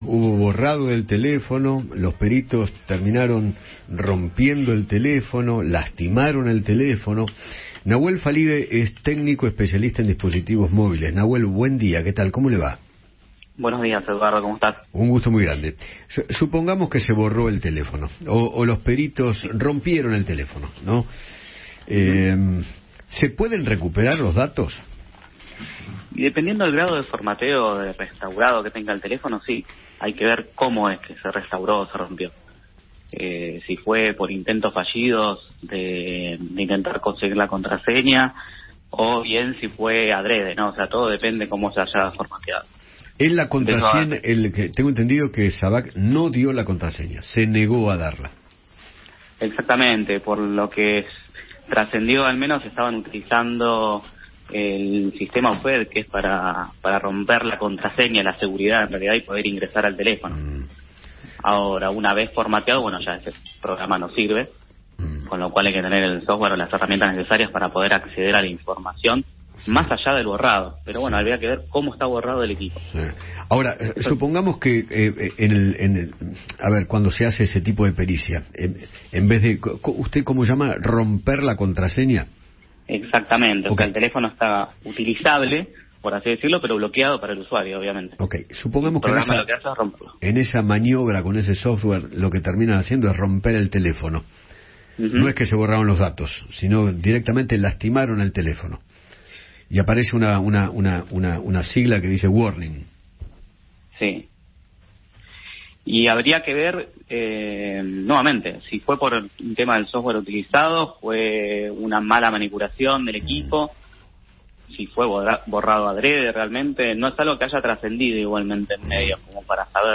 especialista en dispositivos tecnológicos